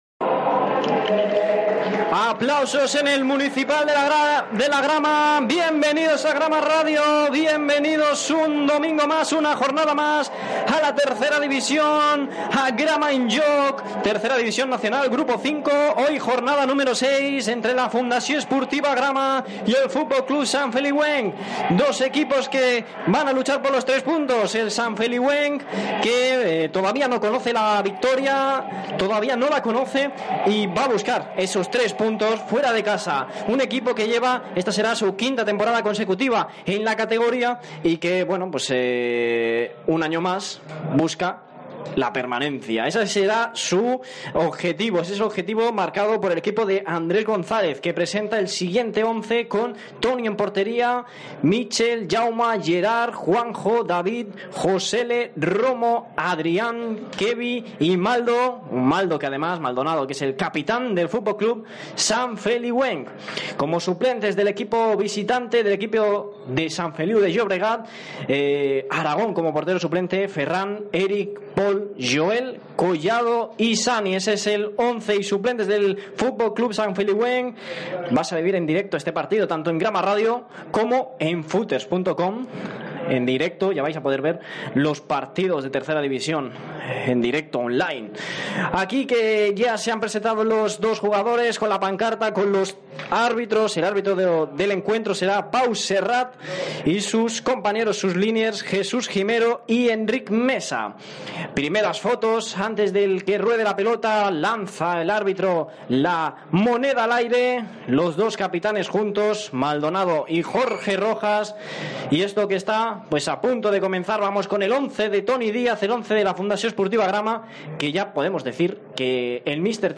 Inici de la transmissió del partit de futbol masculí Fundació Esportiva Grama - Futbol Club Sant Feliuenc de la Jornada 6 del Grup 5 de Tercera Divisió, disputat al Municipal de la Grama, Santa Coloma de Gramenet.
Esportiu